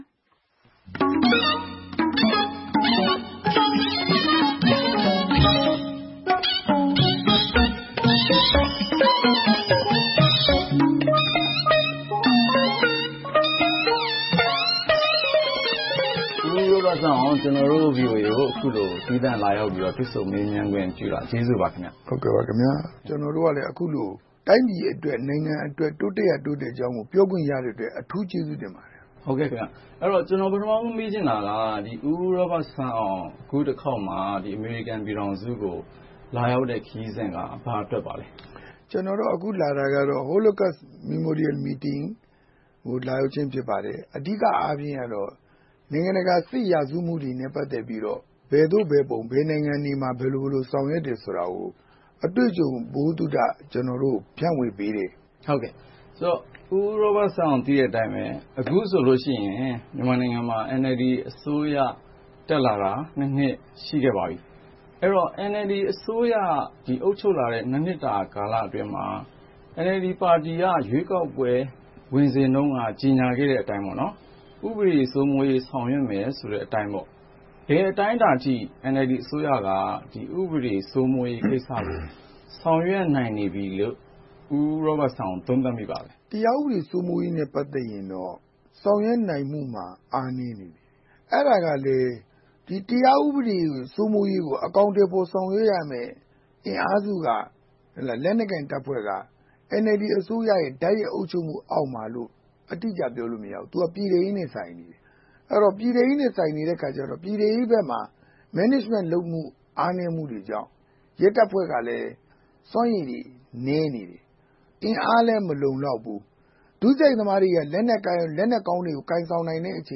ဗွီအိုအေစတူဒီယိုမှာ